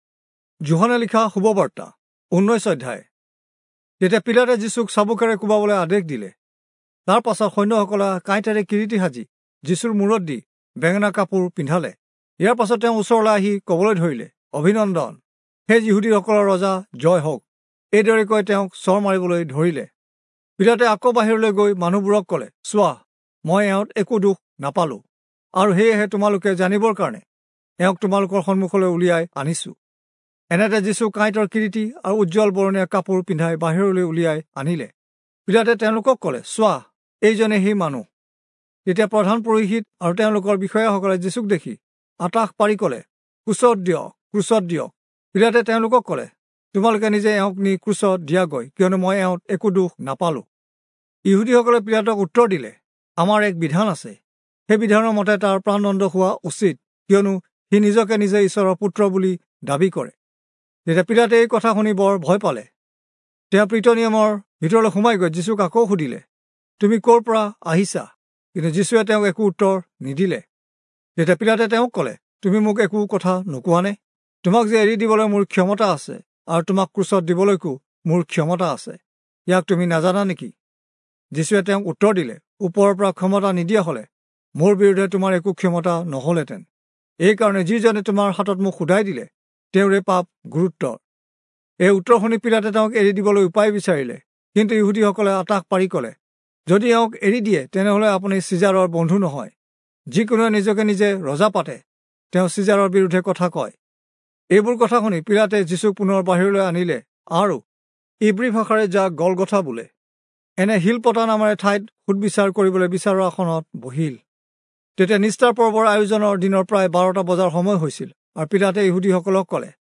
Assamese Audio Bible - John 6 in Irvta bible version